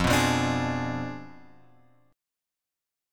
F Diminished 7th